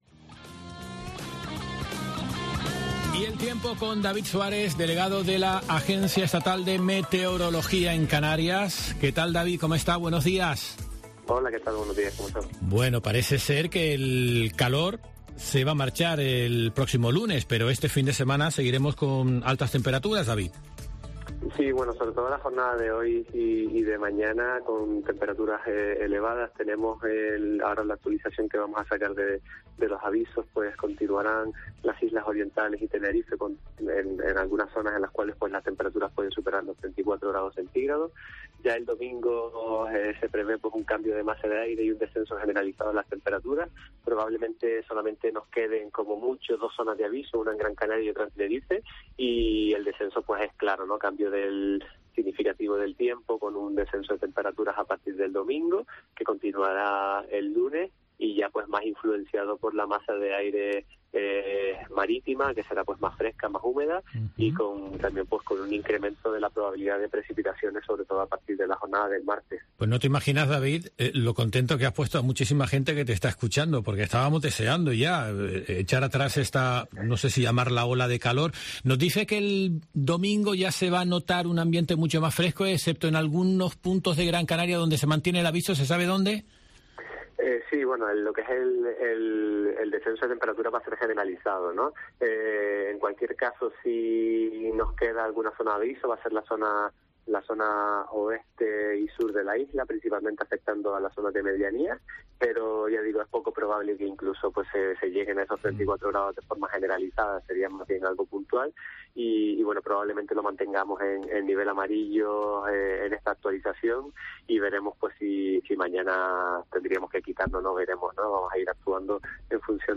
Gran Canaria